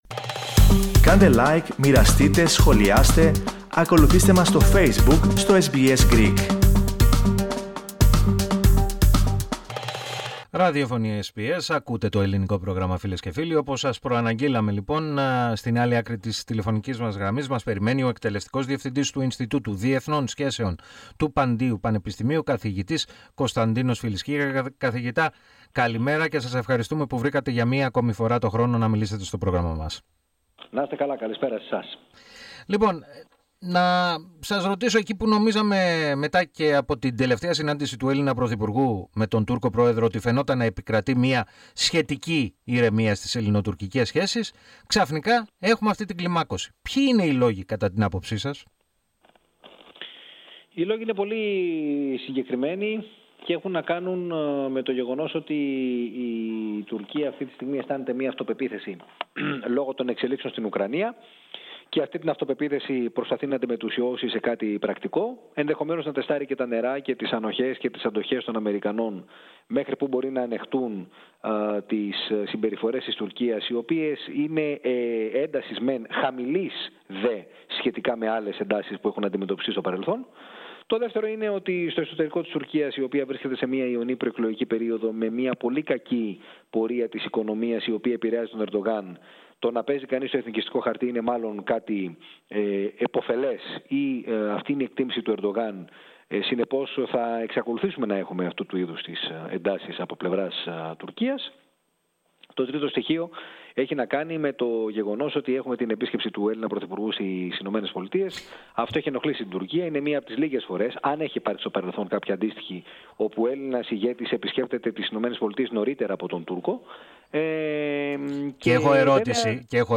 μίλησε στο Ελληνικό Πρόγραμμα της ραδιοφωνίας SBS